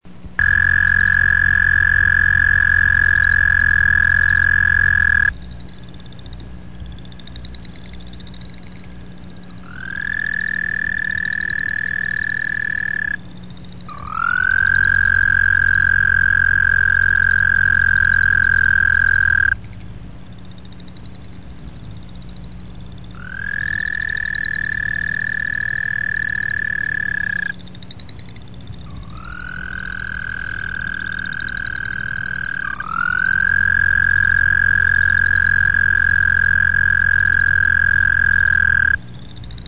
Toads!Canadian Toad from nature North.
Canadian_Toad.mp3